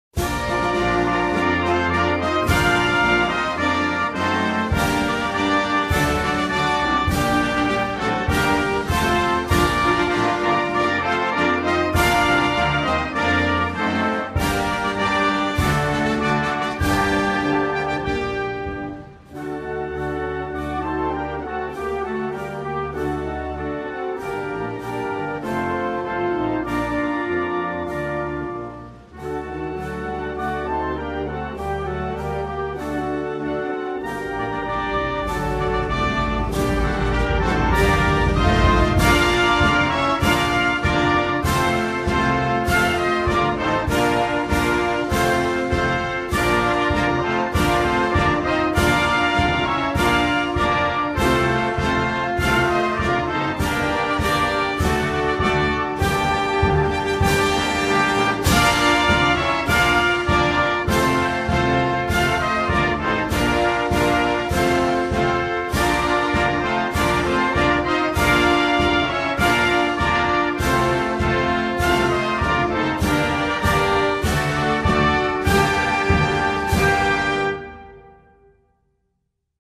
Українські патріотичні рингтони